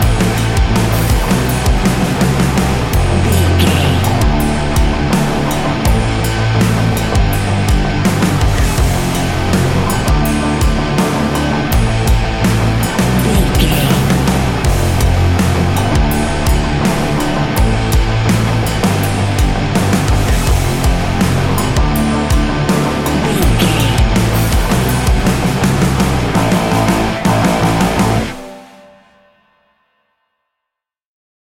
Ionian/Major
D♭
hard rock